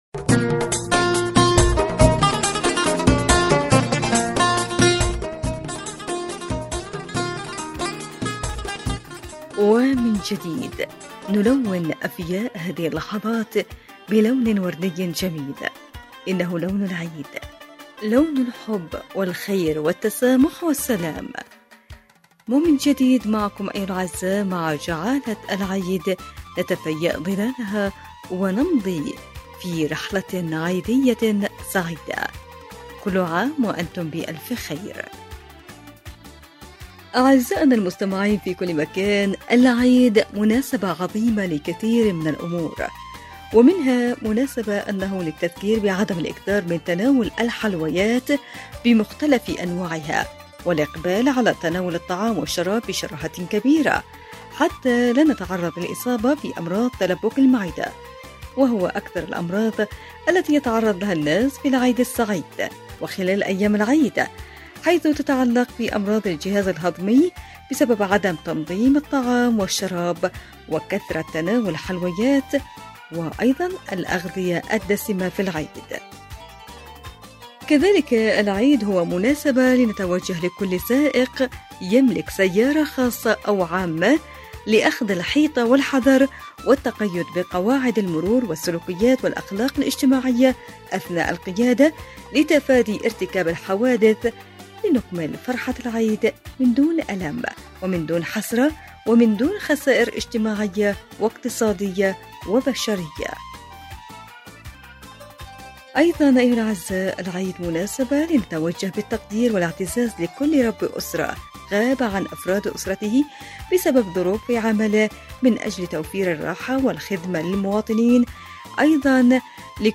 برنامج : المرأة في اسبوع .. جديد الاخبار والدراسات والاكتشافات العلمية التي تخص المرأة .. نشرة اخبارية تهتم بأخبار المرأة اليمنية والفعاليات والانشطة التي تشارك فيها وتخصها